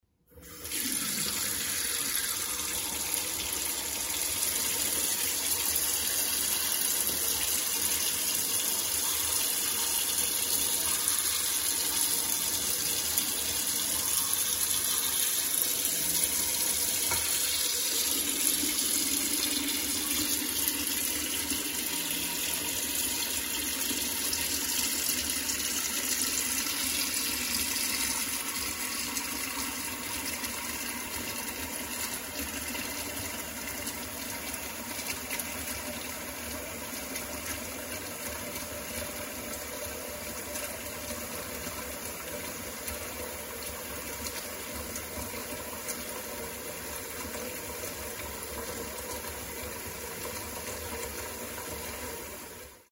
Звуки водяного насоса
На этой странице собраны звуки работы водяных насосов разных типов.